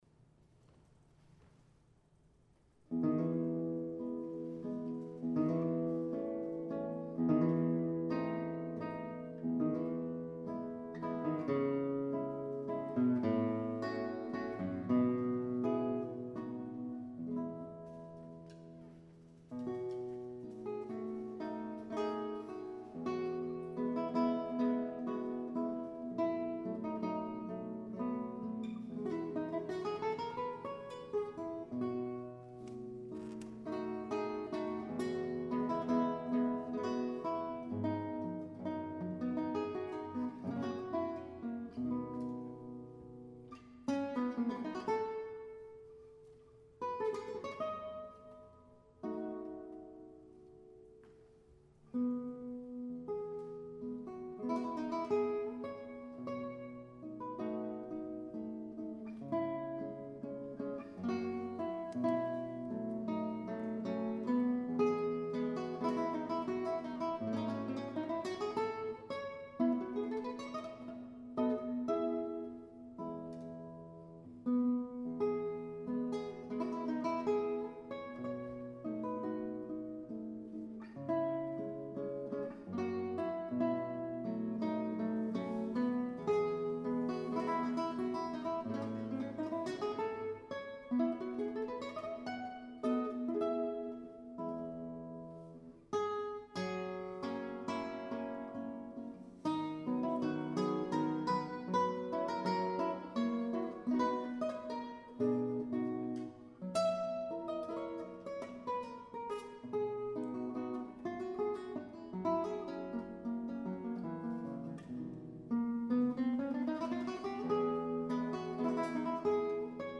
Amazing Classical Guitars and Multi-Strings
Fantasia (11 string)